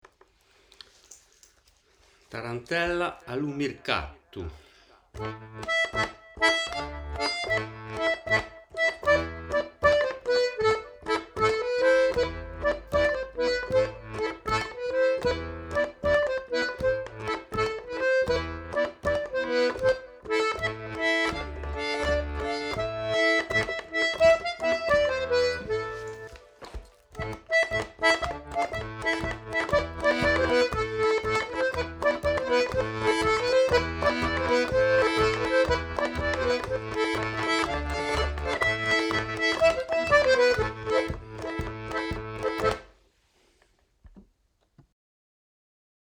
1 - A LU MIRCATU / TARENTELLE COLLECTÉE À SAINT-ÉTIENNE
- A lu Mircatu - mp3 à l'accordéon diatonique (en Lam)
Tarentella A Lu Mircatu.mp3